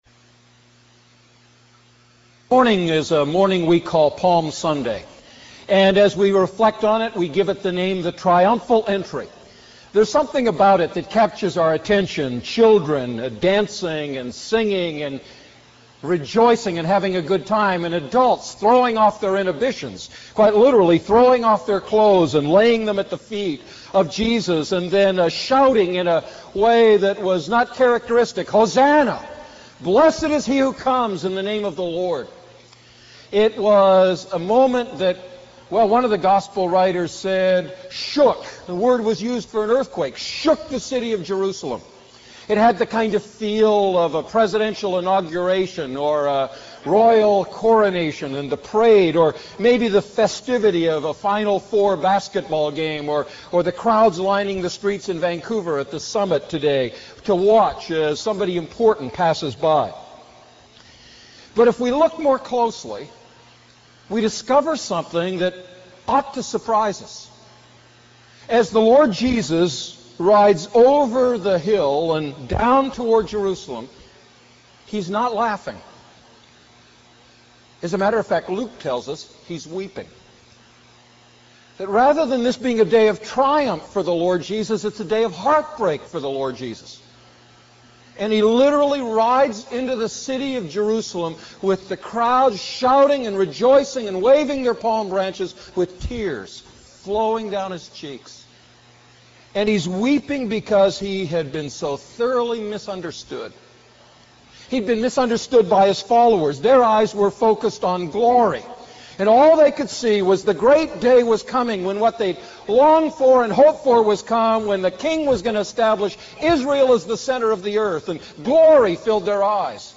A message from the series "I Peter Series."